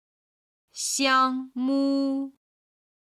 今日の振り返り！中国語発声
01-xiangpu.mp3